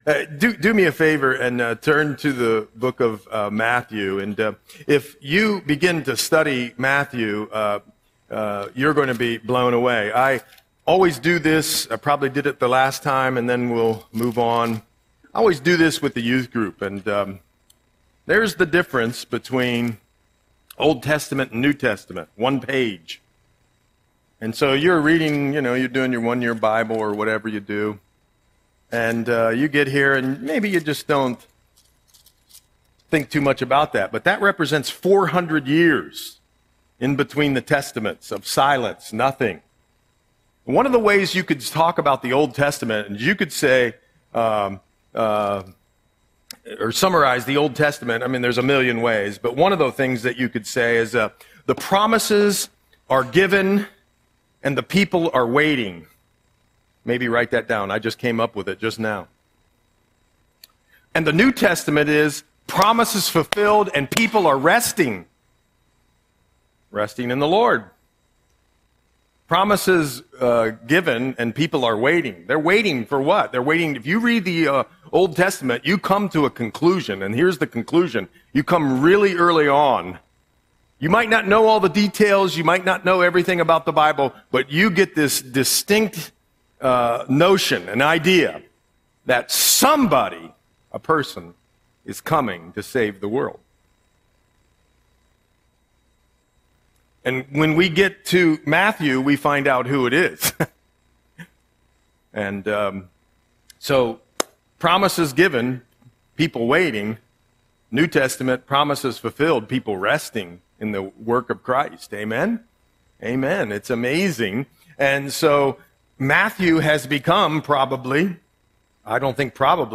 Audio Sermon - September 21, 2025